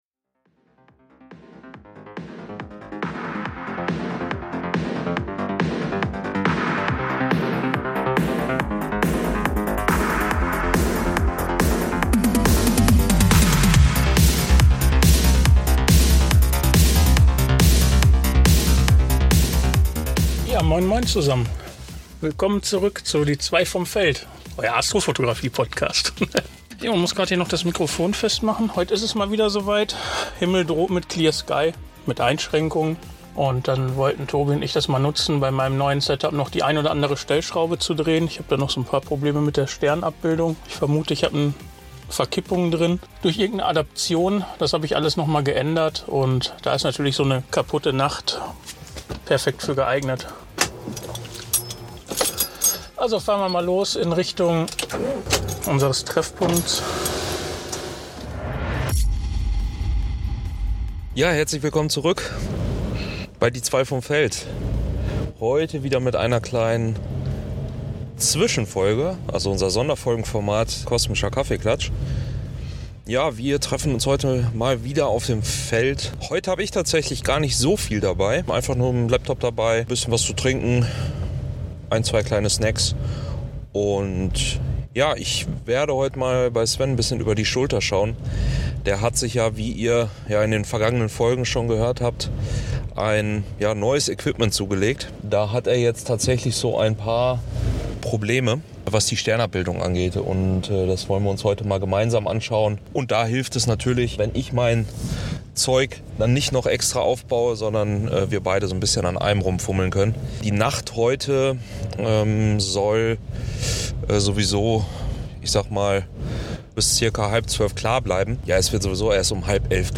Zwischen unseren Gesprächen auf dem Feld nehmen wir euch in dieser Sonderfolge mit in unsere Planungen. Es ist also wieder Zeit für einen Kosmischen Kaffeeklatsch live vom Feld.